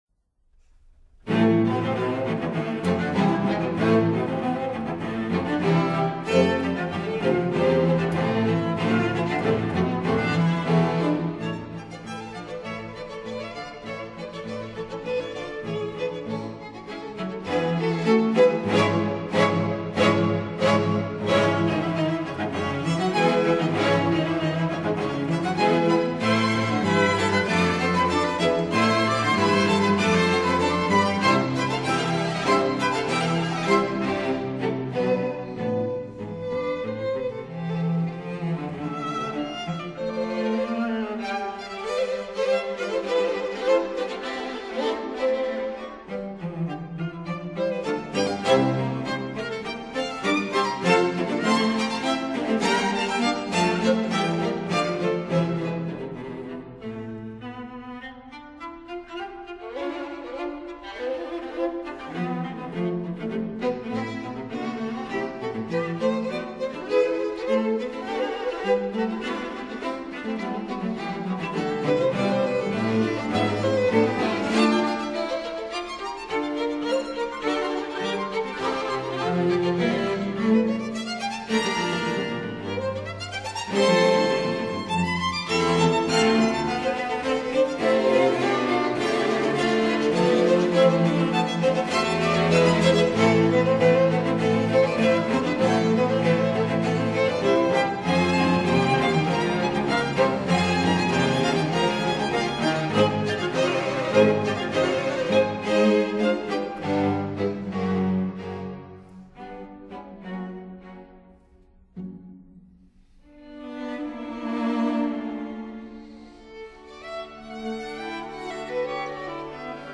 viola
cello